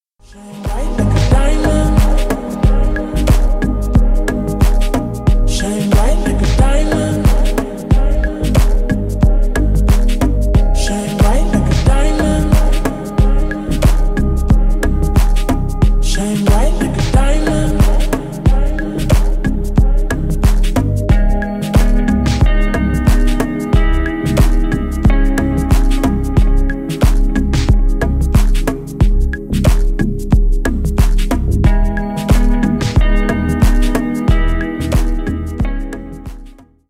deep house
ремиксы